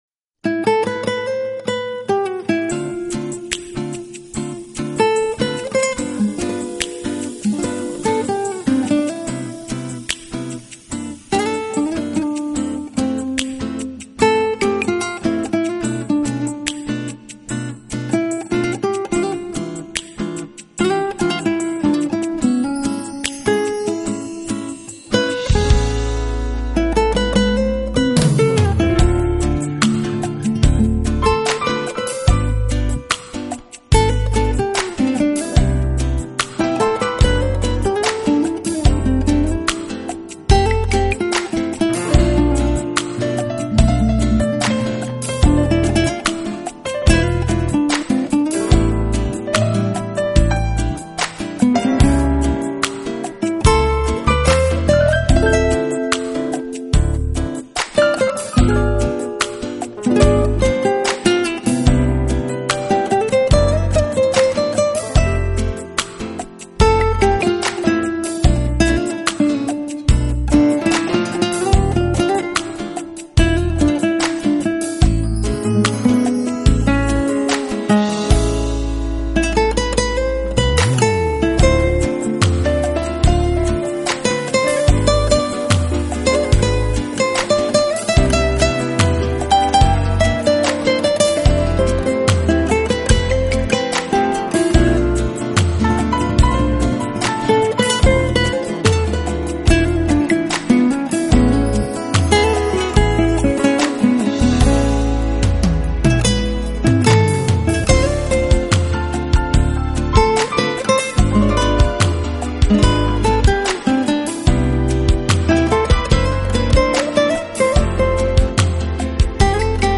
and his trademark cool and funky hooks.